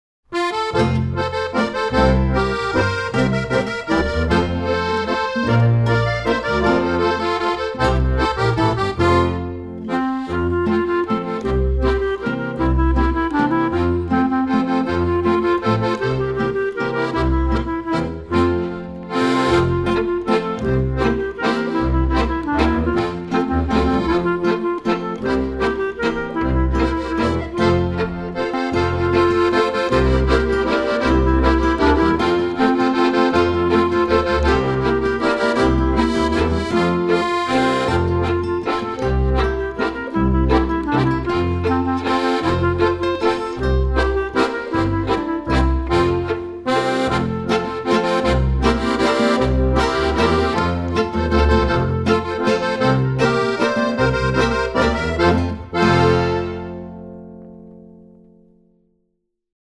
Playback , konstantes Tempo
08HeyRackabackaTempokonstant_PB_BLKM.mp3